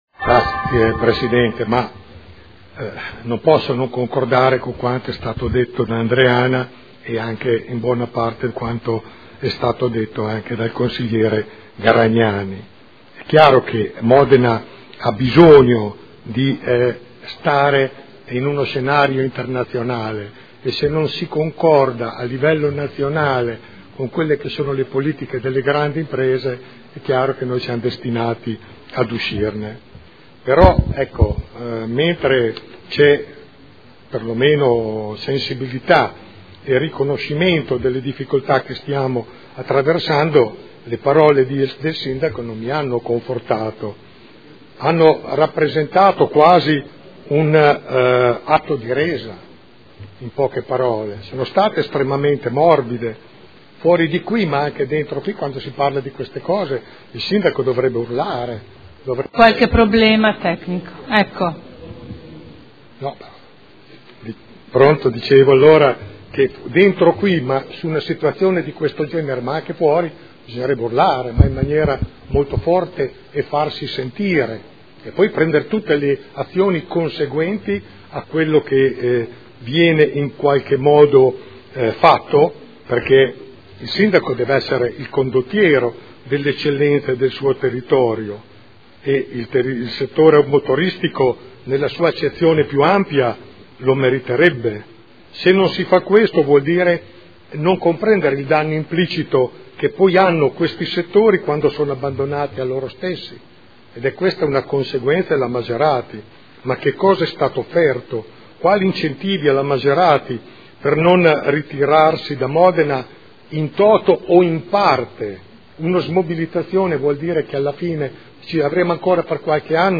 Gian Carlo Pellacani — Sito Audio Consiglio Comunale